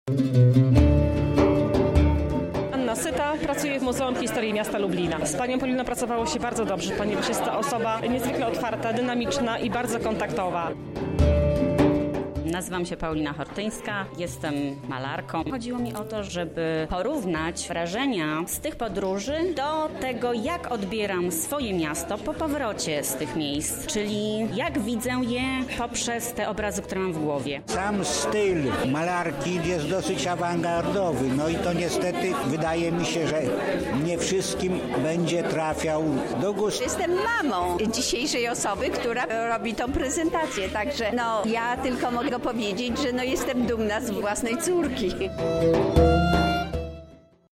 Wernisaż „Ślady”